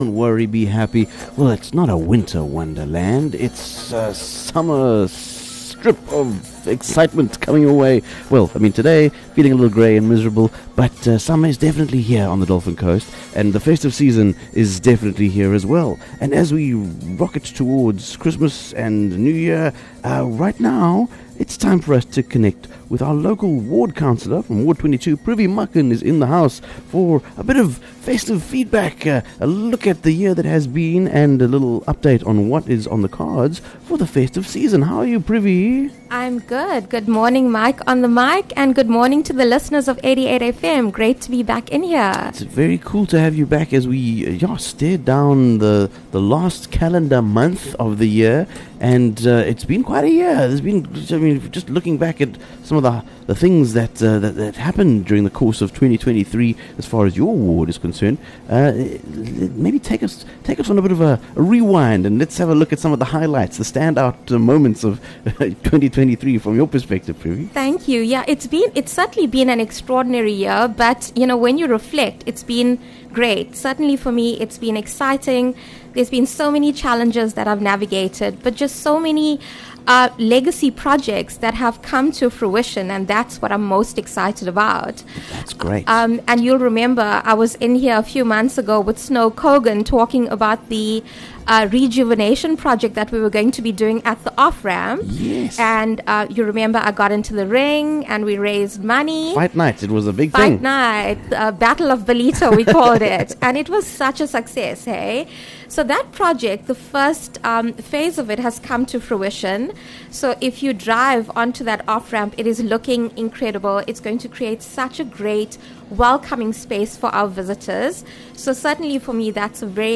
11 Dec Clr Privi Makan on the Morning Show